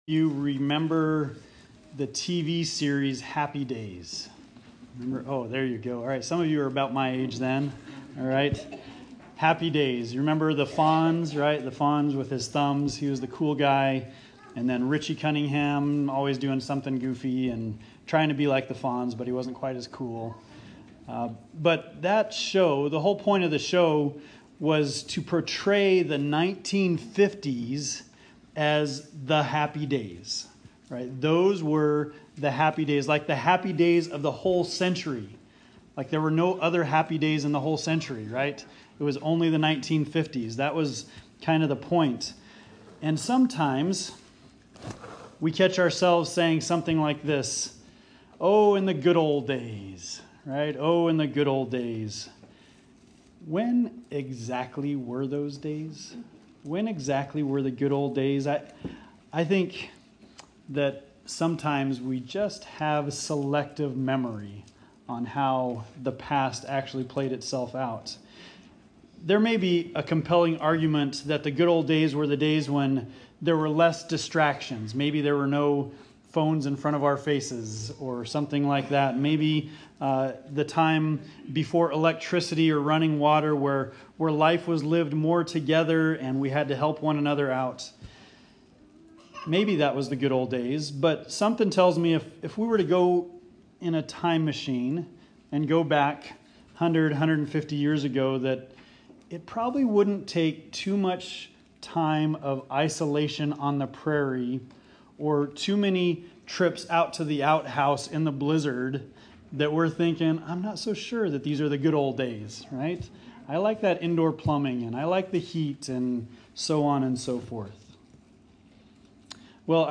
Bible Text: 1 Peter 3:10-12 | Preacher